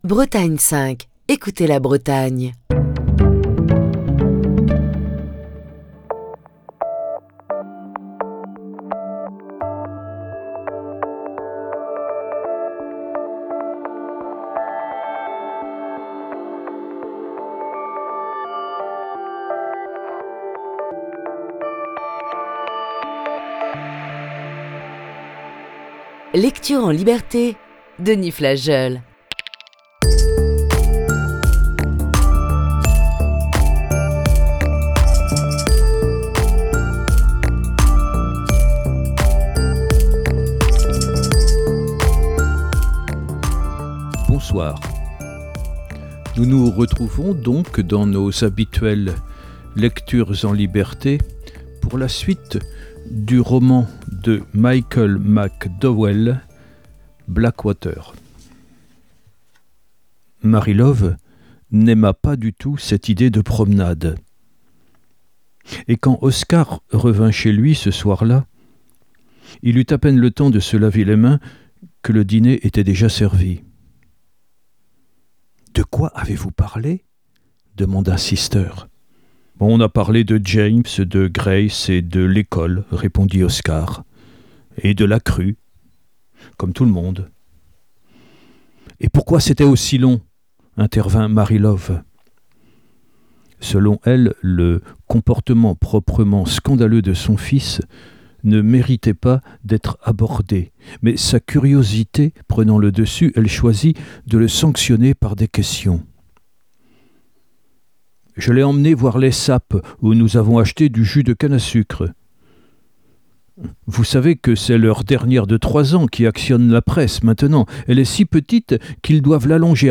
Voici ce soir la sixième partie de ce récit.